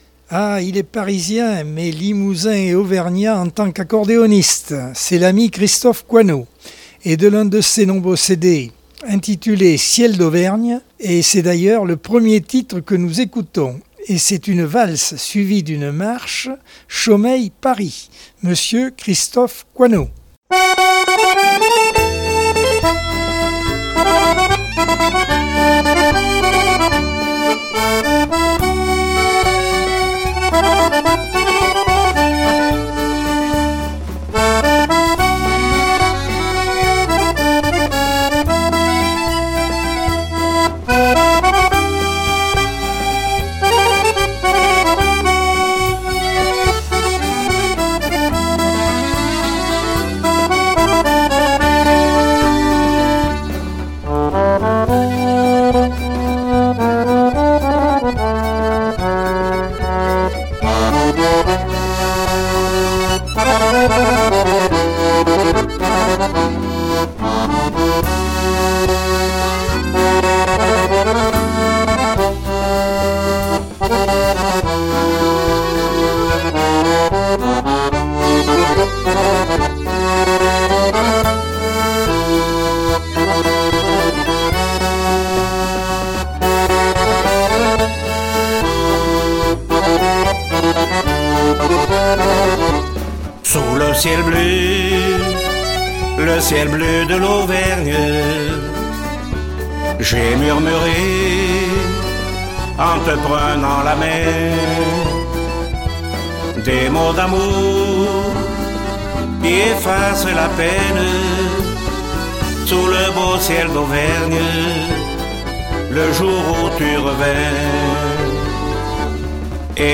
Accordeon 2022 sem 29 bloc 4 - Radio ACX